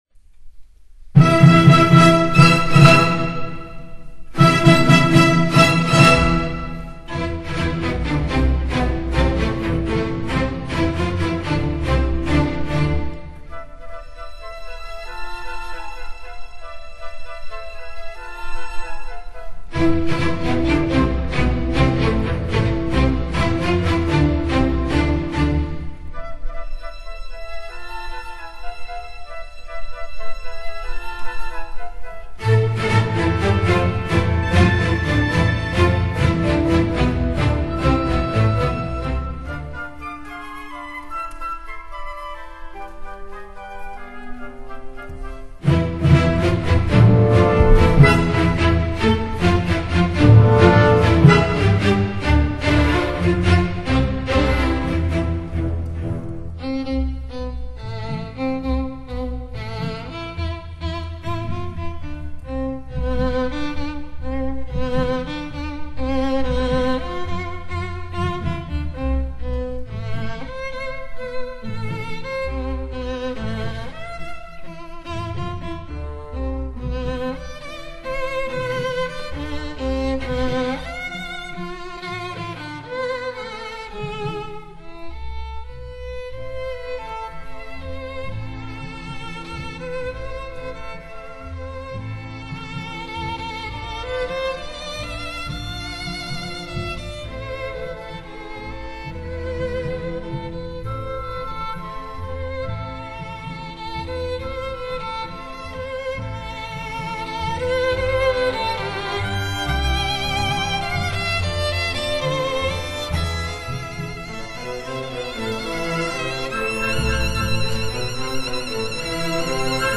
无论如何，这首曲子是非常好听的，每个乐章都十分浪漫，众多优美的曲调以及整体气氛都十分诱人，警官在意境上略嫌单薄。